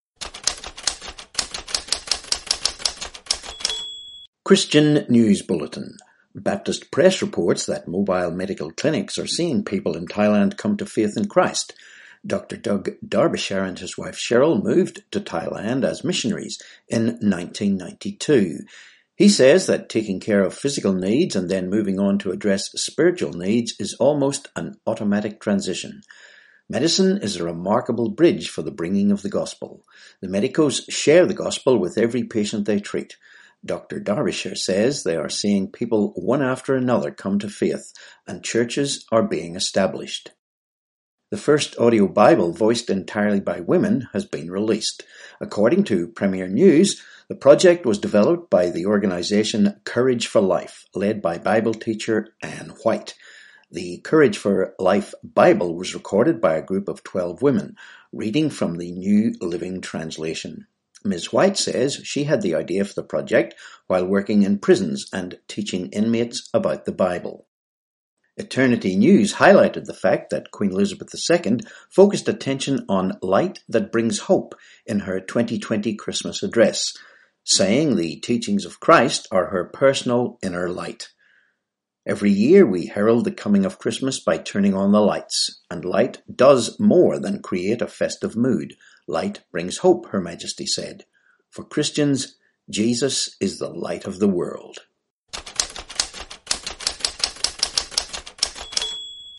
3Jan21 Christian News Bulletin